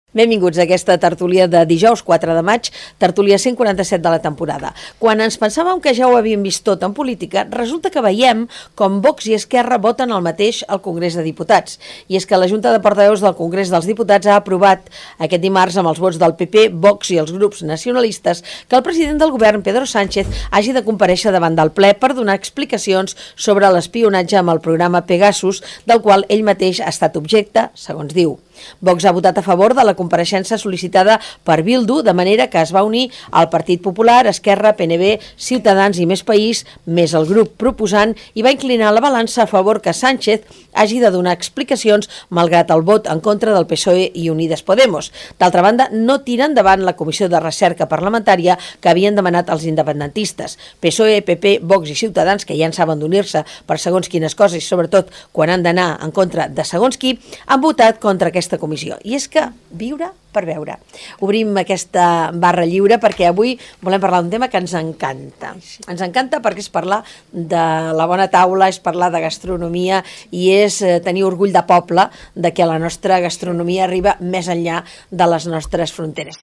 Barra Lliure: editorial - Ràdio Manresa, 2022
Àudios: arxiu sonor de Ràdio Manresa